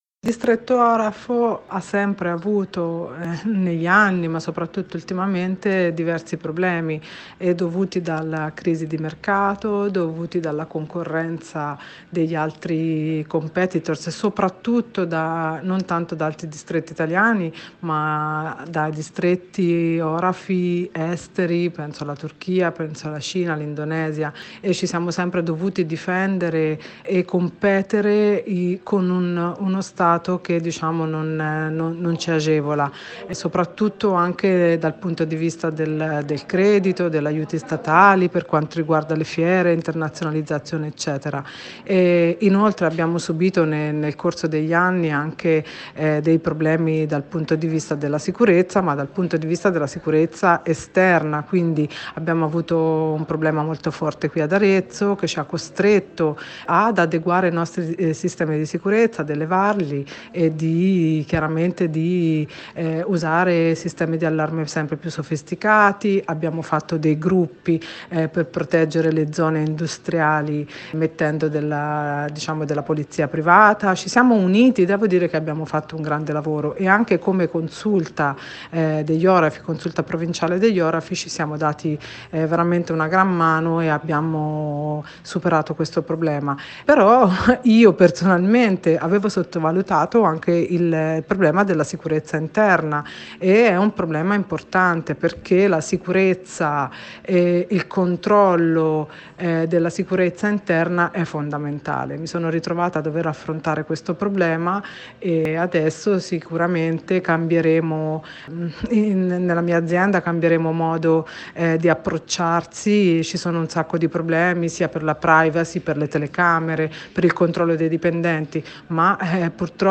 Lucia Tanti, Assessore alle Politiche sociali, Famiglia, Scuola, Politiche sanitarie del Comune di Arezzo, è intervenuta relativamente alla questione della supermensa spiegando la bocciatura della proposta del comitato ‘Giù le mani dalle mense’ da parte del Consiglio Comunale e illustrando quella che ha definito “una vittoria della città ed una rivoluzione tranquilla”.